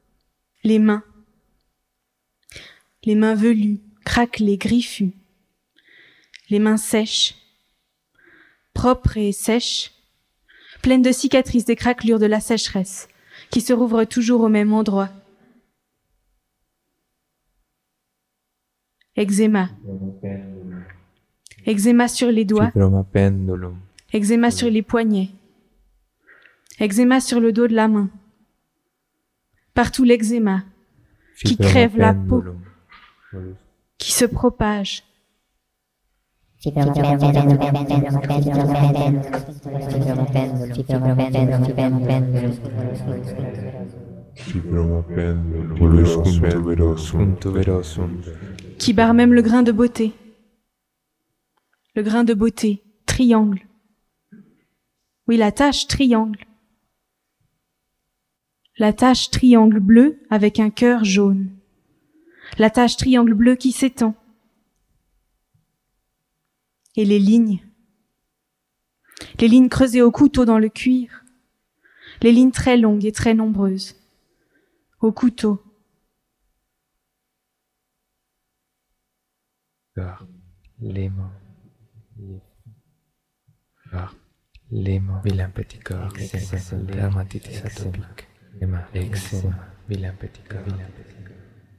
performance multimédia